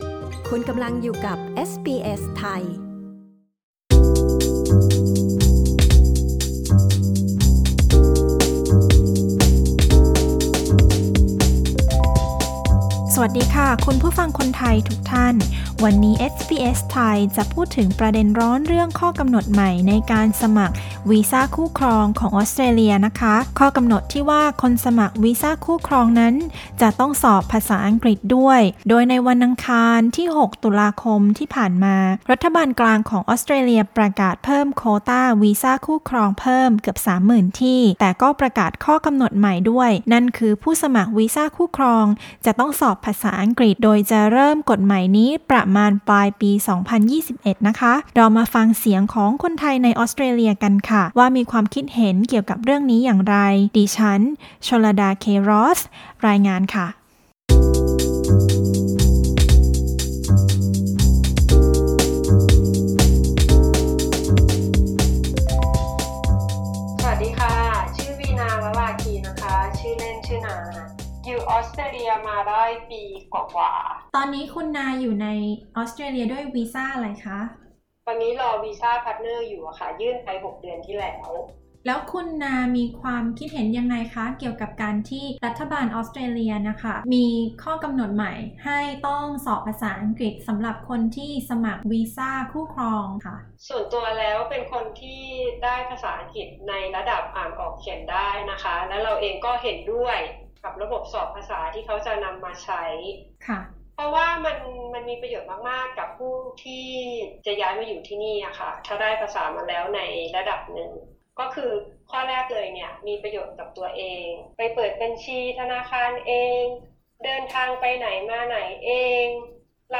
ฟังเสียงความคิดเห็นของคนไทยส่วนหนึ่งที่ยื่นวีซ่าคู่ครอง หลังรัฐบาลออสเตรเลียประกาศเพิ่มโควต้าวีซ่านี้ และจะเพิ่มกฎใหม่ให้ผู้สมัครต้องสอบภาษาอังกฤษด้วย พวกเขาเห็นด้วยหรือไม่ และมีข้อเสนอทางเลือกสำหรับคนที่อาจจะสอบไม่ผ่านอย่างไร